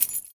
R - Foley 17.wav